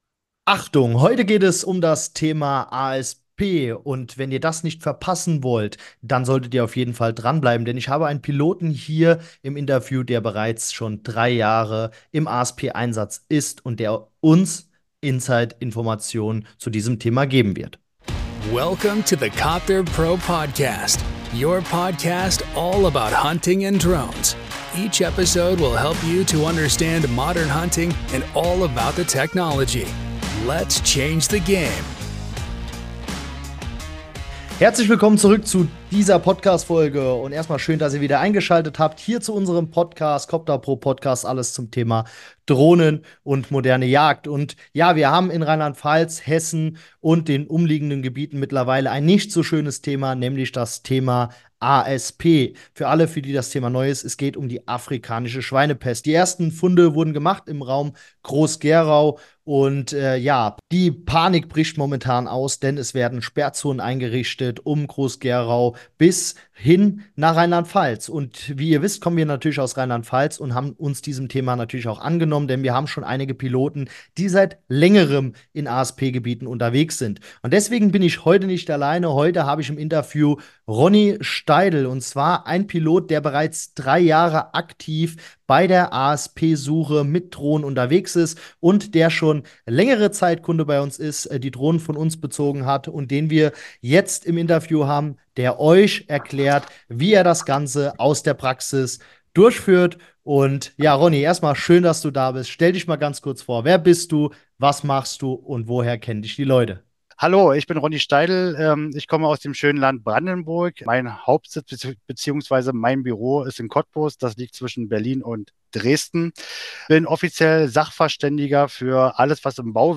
#110 Interview mit Drohnenpilot aus einem ASP-Gebiet - So läuft es dort ab! [1/2] ~ Copterpro Podcast: Alles zum Thema Drohnen und moderne Jagd Podcast